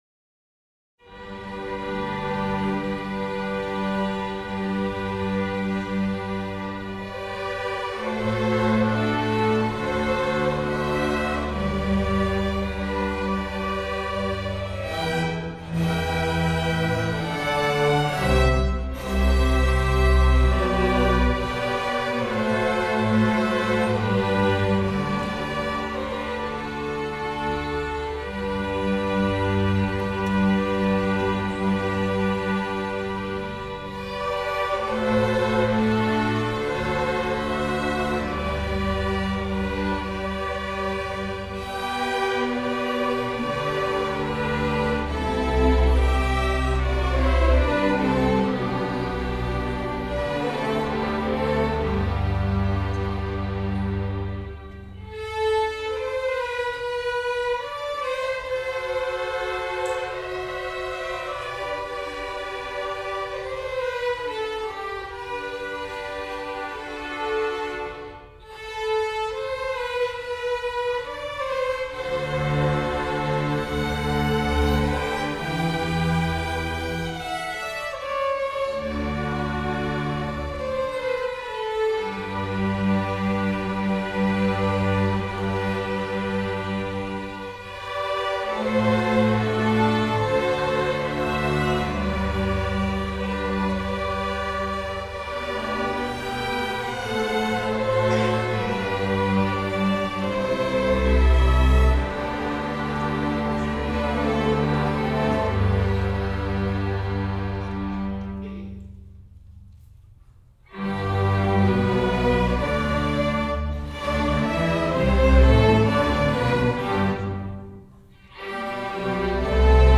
8th Grade Orchestra Concerts 8:00